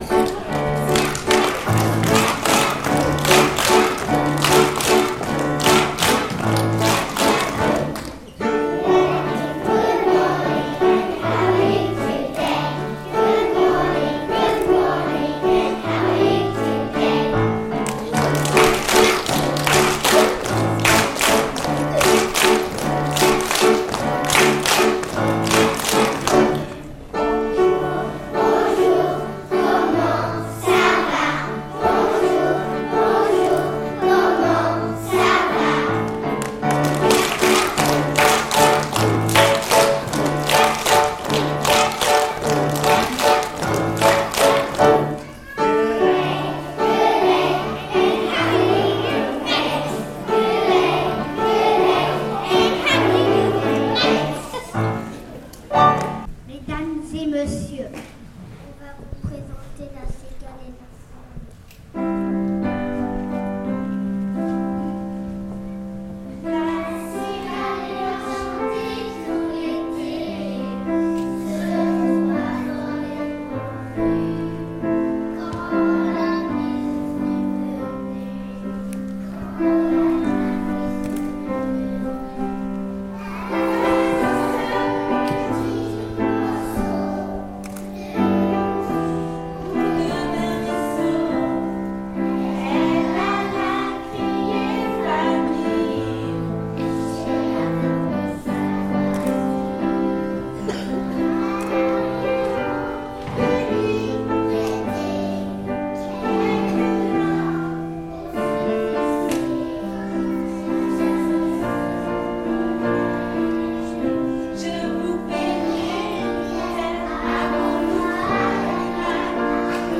Concert en live: les GS